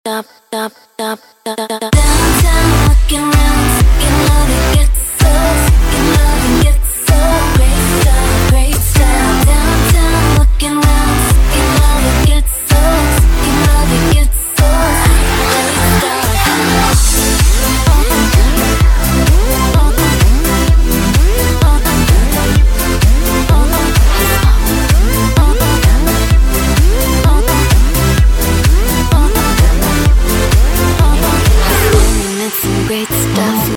• Качество: 192, Stereo
женский вокал
заводные
electro house
Клубная заводная музыка с вокалом